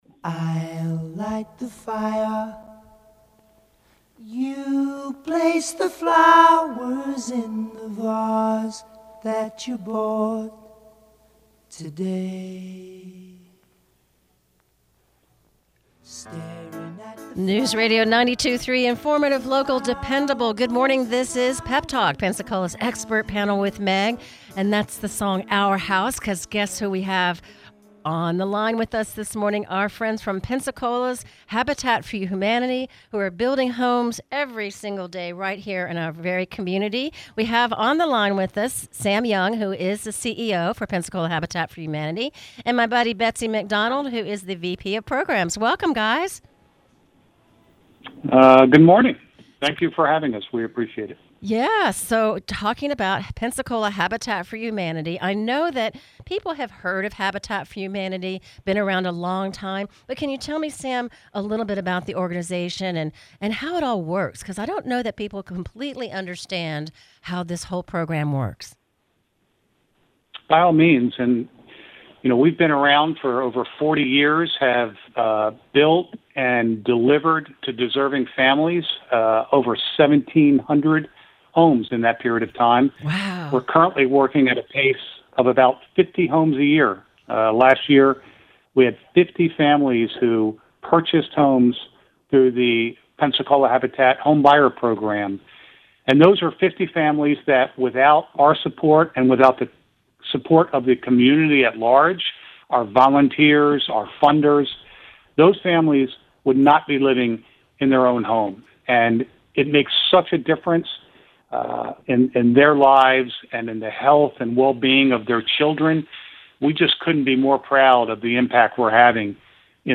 Encore Broadcast